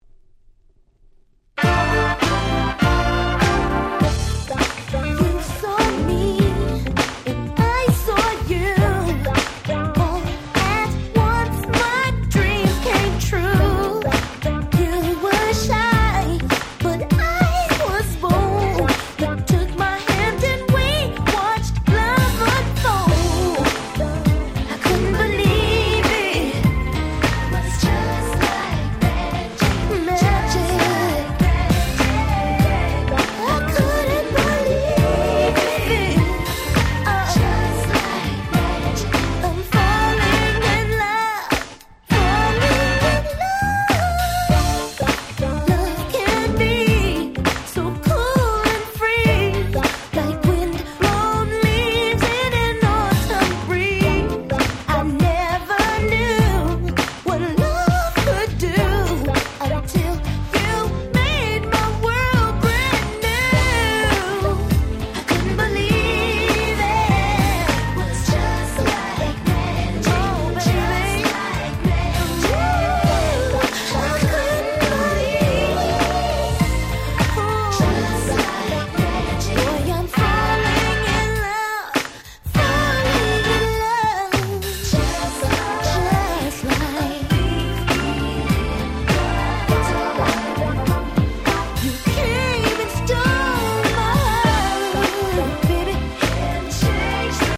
02' Nice Soul/R&B !!
詳細不明ですが、Oallandの黒人のおじさん5人組のバンド。
よりどりみどりな甘い曲調の4曲は派手さは無い物の年季を感じさせる良曲揃い！
NJSっぽくハネてる曲もあったりでなかなかどうして。